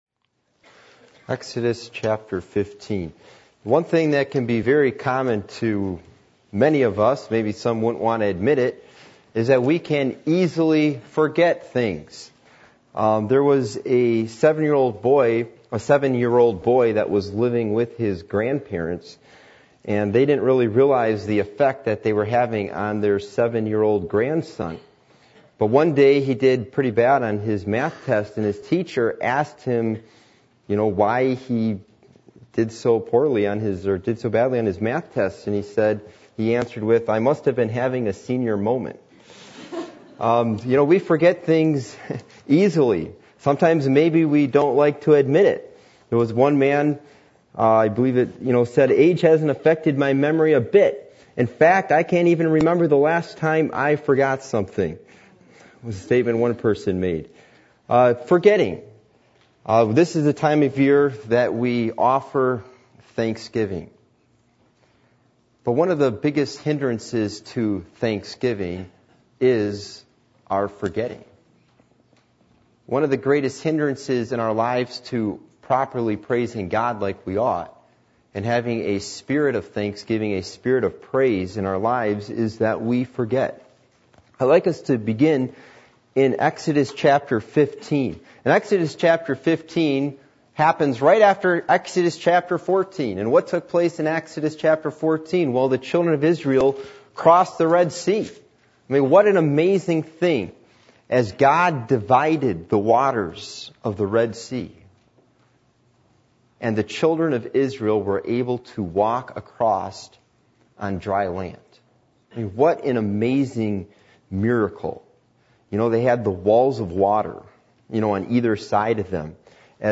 Passage: Exodus 15:1-16:1 Service Type: Midweek Meeting